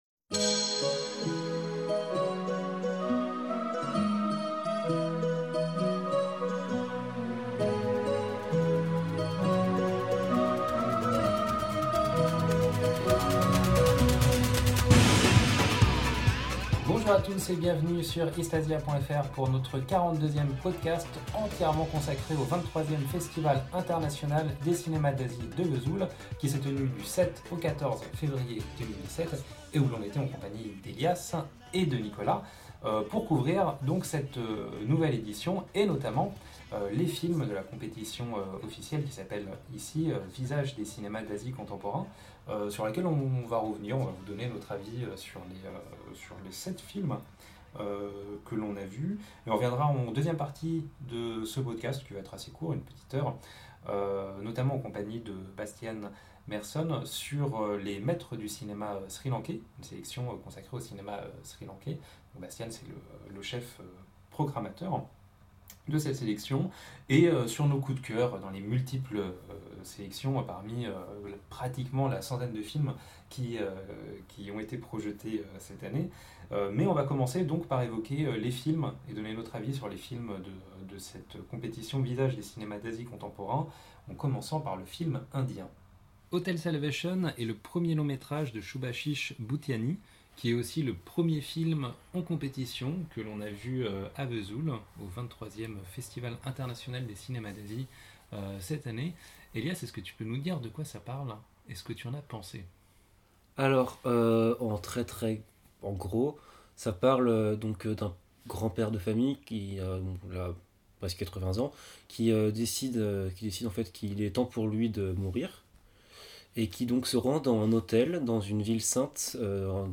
Bonjour à tous, et bienvenus sur East Asia pour notre Podcast en direct du 23ème Festival International des Cinéma d'Asie (FICA) qui s'est tenu à Vesoul du 7 au 14 février.